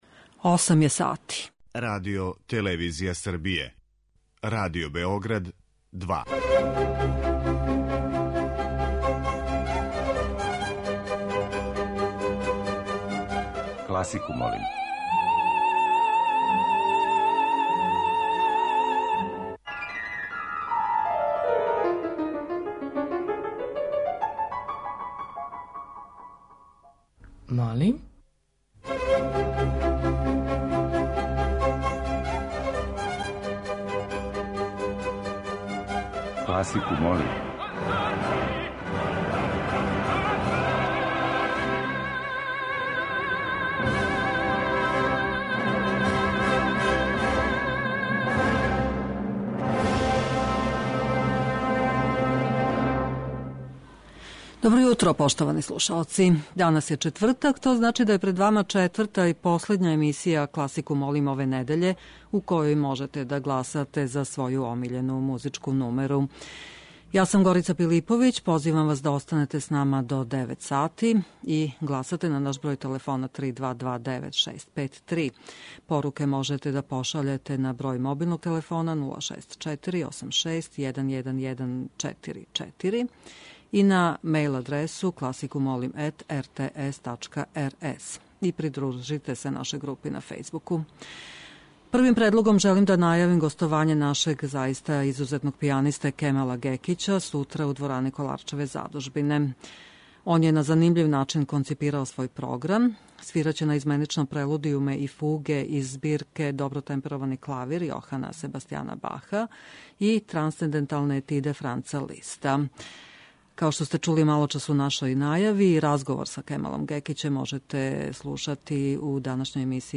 Уживо вођена емисија, окренута широком кругу љубитеља музике, разноврсног је садржаја, који се огледа у подједнакој заступљености свих музичких стилова, епоха и жанрова. Уредници (истовремено и водитељи) смењују се на недељу дана и од понедељка до четвртка слушаоцима представљају свој избор краћих композиција за које може да се гласа телефоном, поруком, имејлом или у ФБ групи.